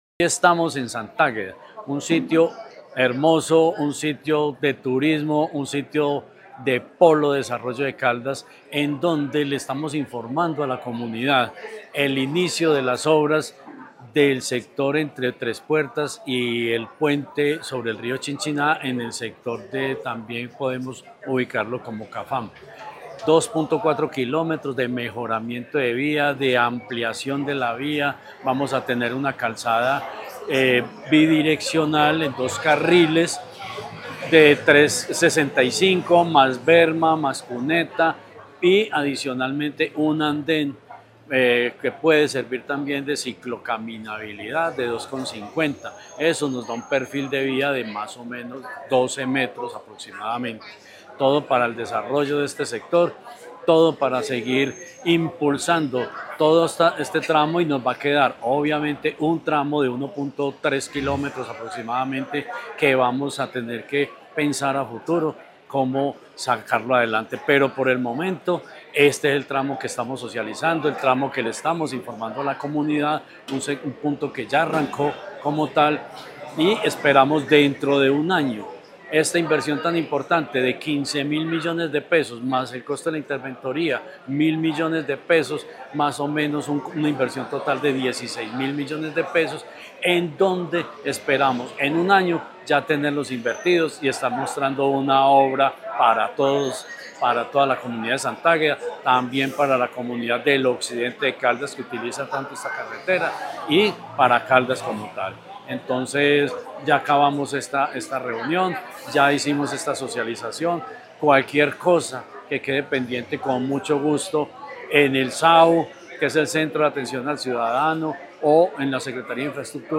Jorge Ricardo Gutiérrez Cardona, secretario de Infraestructura de Caldas